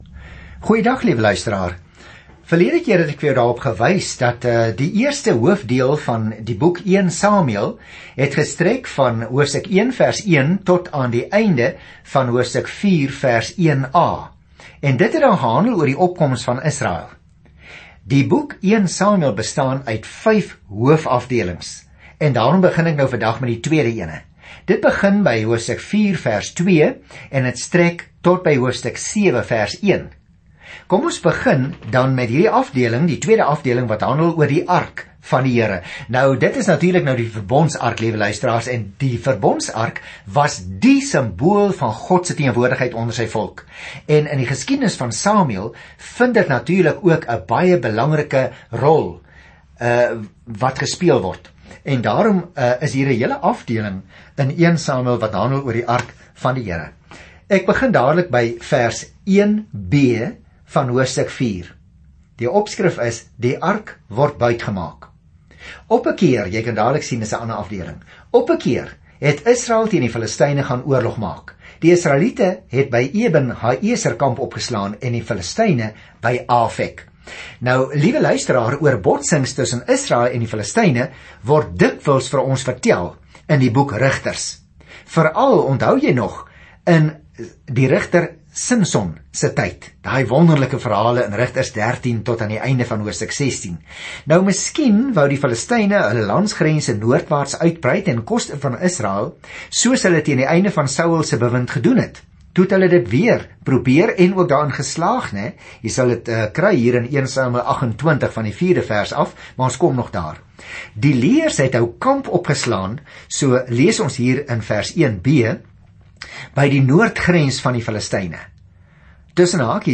Skrif 1 SAMUEL 4:1-22 1 SAMUEL 5 Dag 2 Begin met hierdie leesplan Dag 4 Aangaande hierdie leesplan Eerste Samuel begin met God as Israel se koning en gaan voort met die verhaal van hoe Saul toe Dawid koning geword het. Reis daagliks deur Eerste Samuel terwyl jy na die oudiostudie luister en uitgesoekte verse uit God se woord lees.